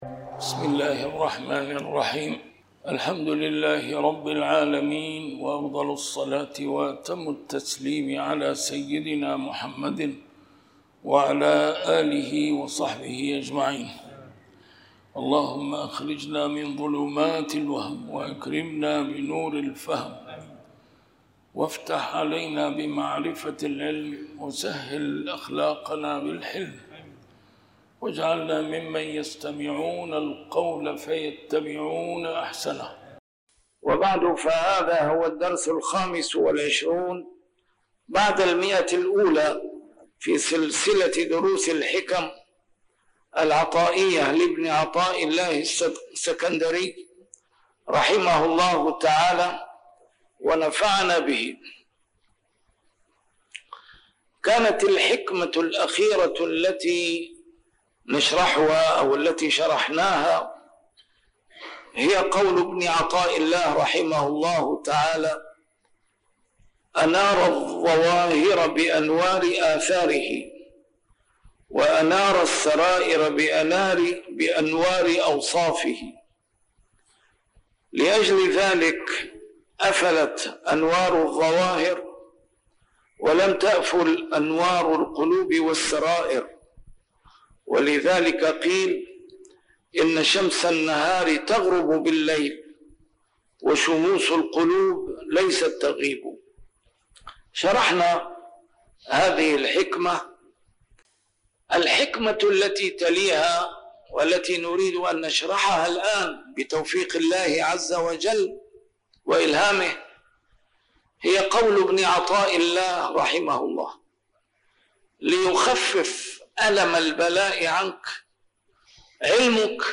نسيم الشام › A MARTYR SCHOLAR: IMAM MUHAMMAD SAEED RAMADAN AL-BOUTI - الدروس العلمية - شرح الحكم العطائية - الدرس رقم 125 شرح الحكمة 104+105